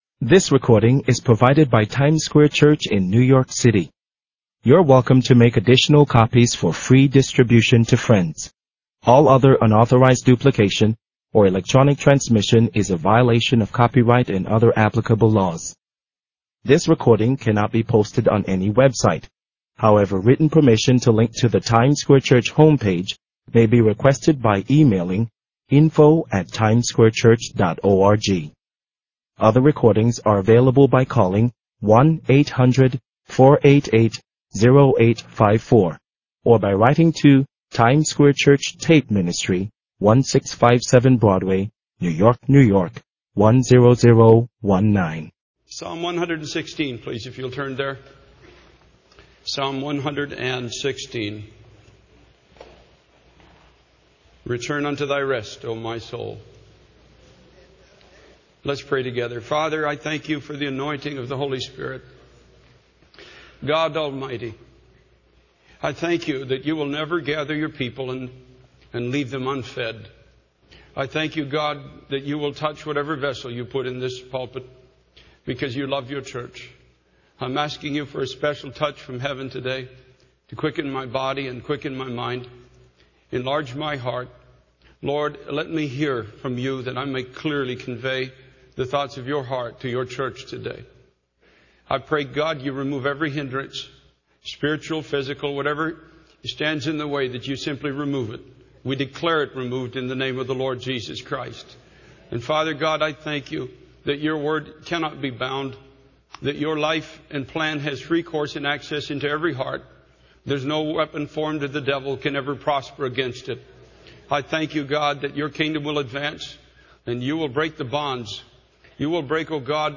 In this sermon, the speaker emphasizes that God waits for the cry of His people, when they come to the realization that they cannot fulfill their own promises to Him. The speaker explains that Jesus came to make a way for believers to have a relationship with God and become partakers of His divine nature.